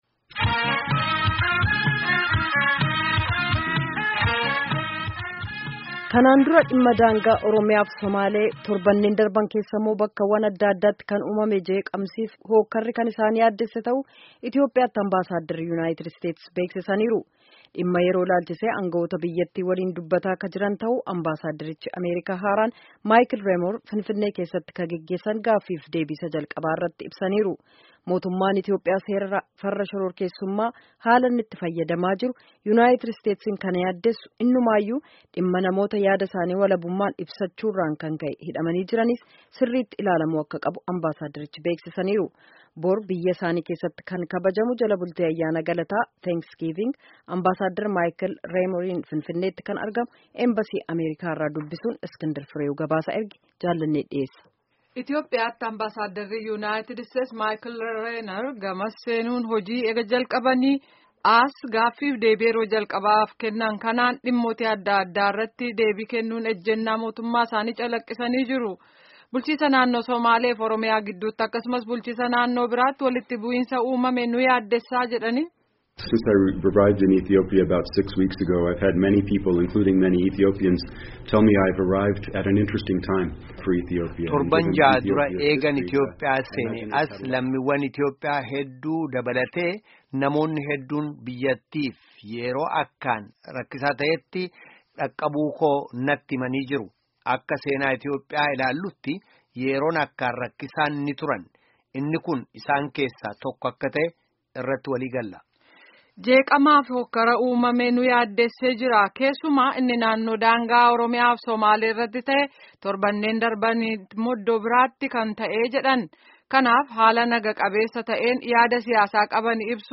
Gabaasa Finfinnee irraa ergamee caqasaa